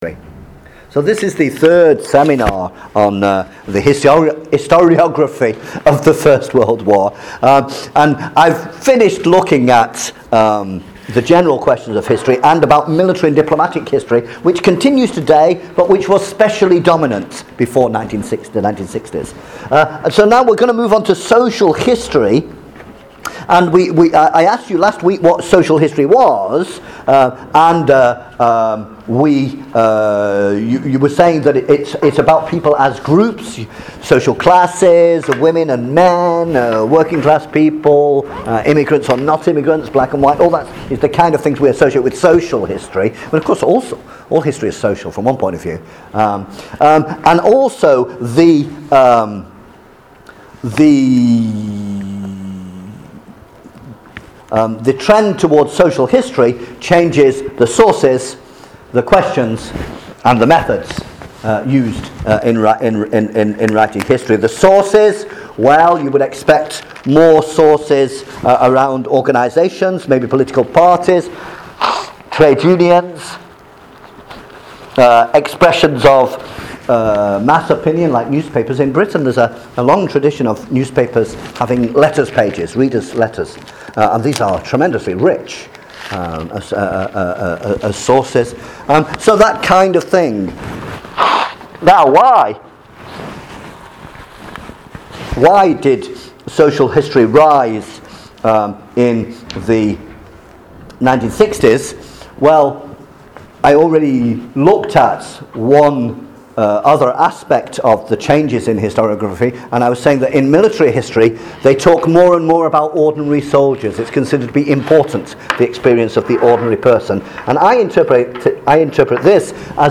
Master research seminar: introduction to historiography